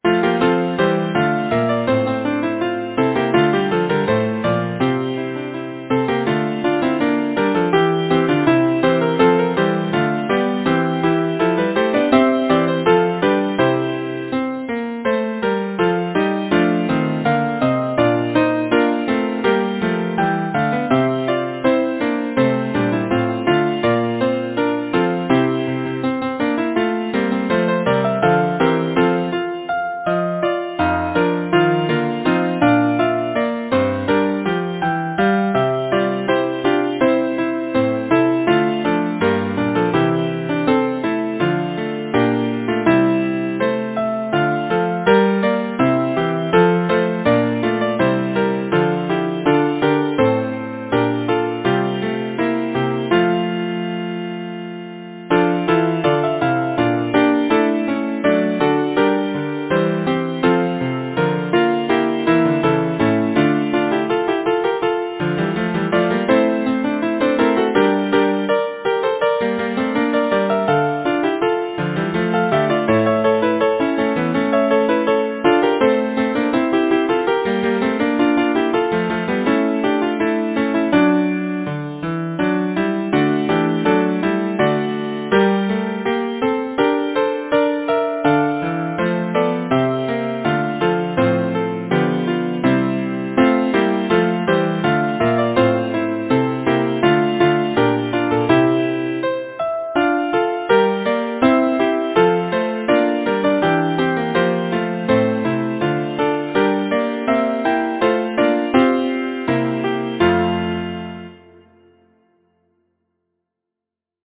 Number of voices: 4vv Voicing: SATB Genre: Secular, Partsong
Language: English Instruments: a cappella or Keyboard